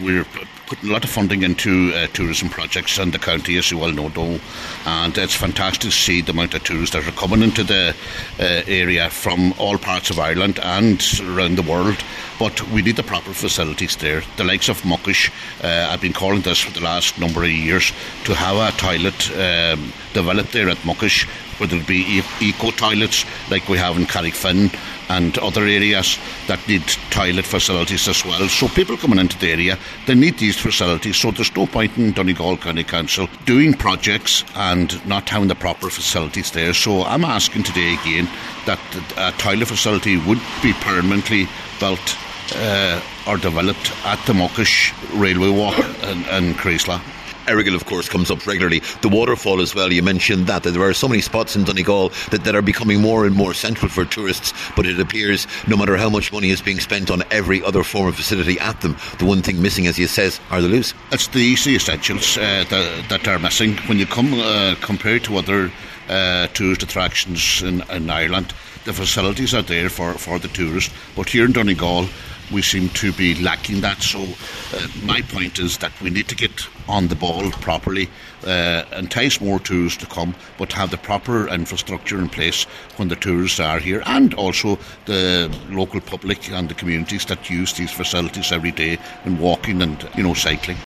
Cllr John Sheamais O’Fearraigh told a meeting of Glenties Municipal District that locations such as the Railway walk on Muckish and Assaranca Waterfall near Ardara need facilities.